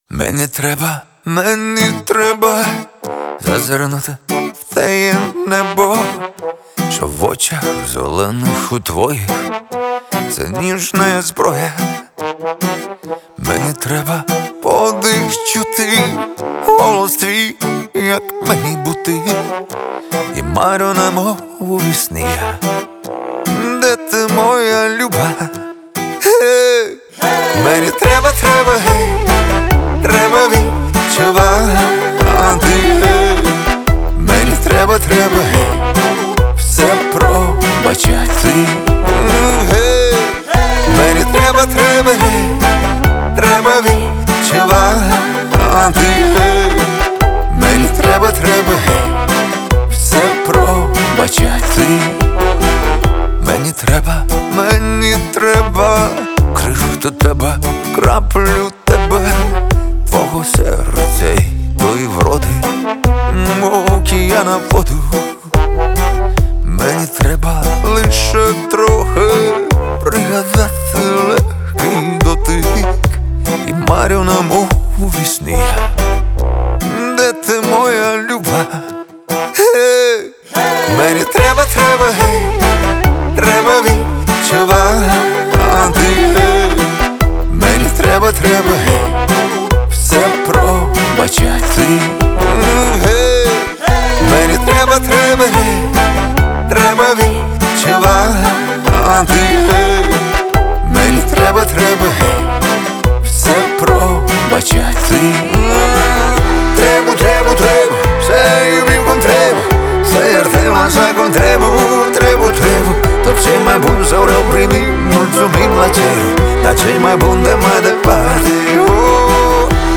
это эмоциональная и мелодичная композиция в жанре поп